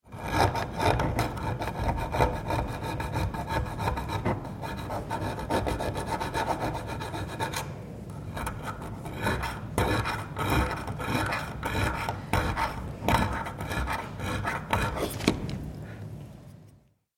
Filing Bronze 2